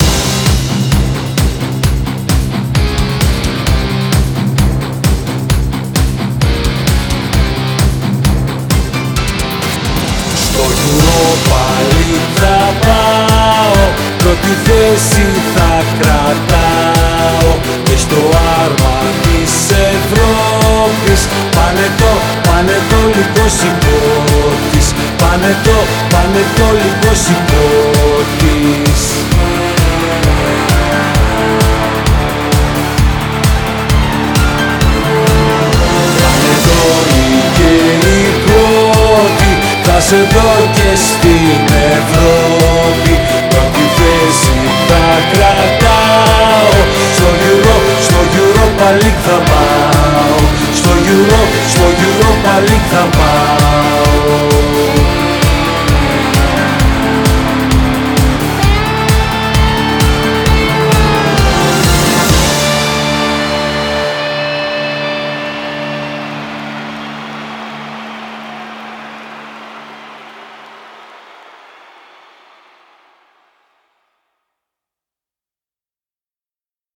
Ηλεκτρική Κιθάρα